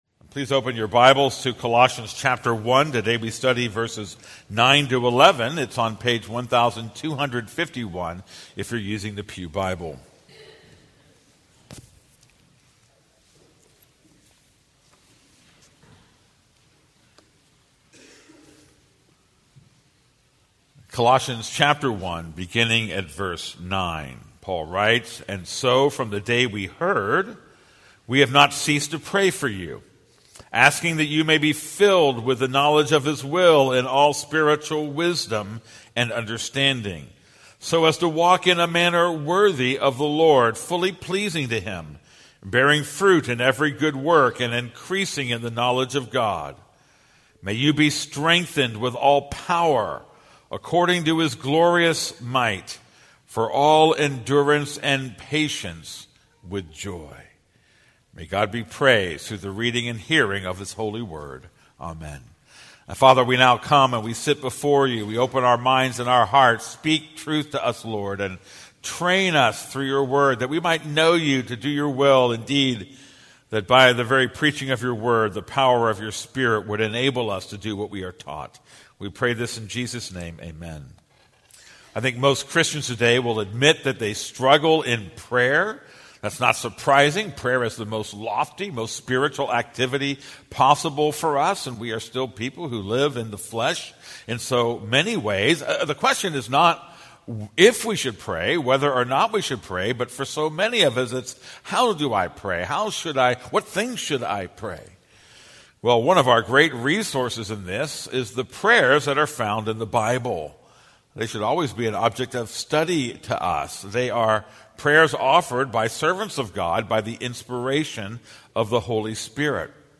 This is a sermon on Colossians 1:9-11.